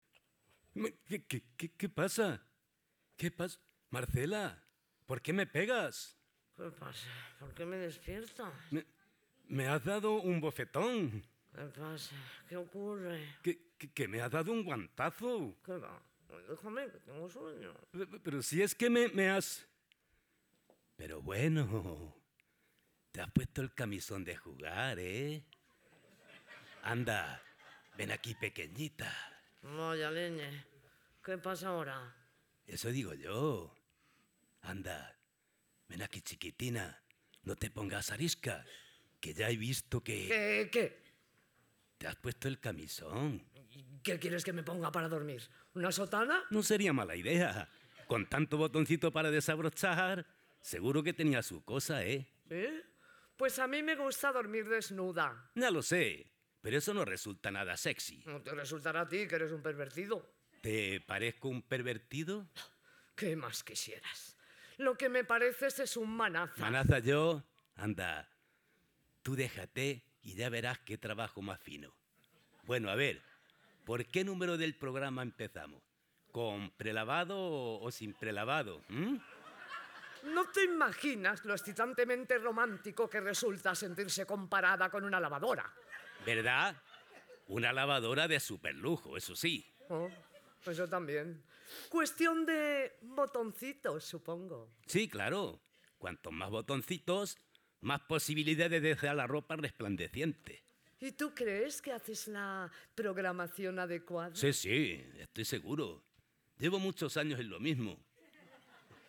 En esta sexta edición de ‘Las manos a escena’, el jurado ha valorado muy positivamente el ritmo de lectura, nivel de interpretación, dicción, ambientación sonora y calidad del sonido de los trabajos presentados.
“Un boquete en el muro”, de Emma Tamargo. El jurado ha destacado “la gran labor de edición y grabación, destacando especialmente la ambientación, con la que han logrado que podamos hacer un muy buen seguimiento espacial, todo muy bien ubicado en el espacio.